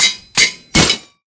anvil_break.ogg